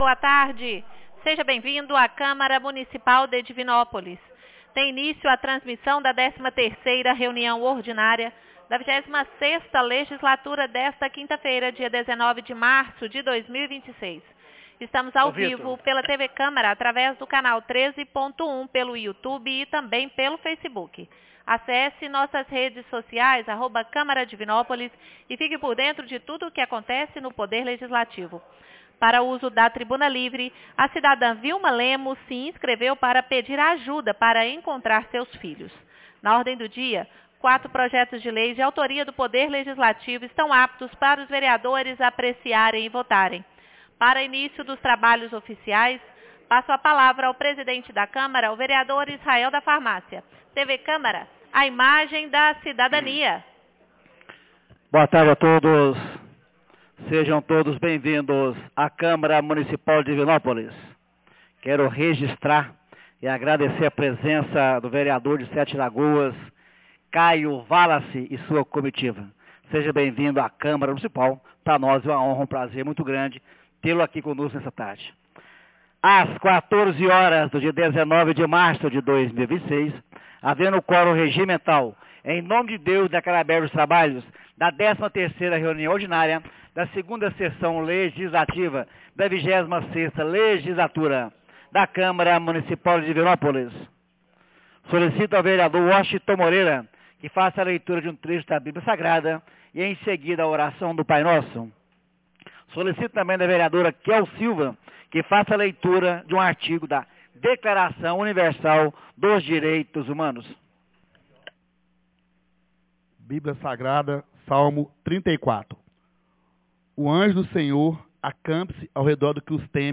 13ª Reunião Ordinaria 19 de março de 2026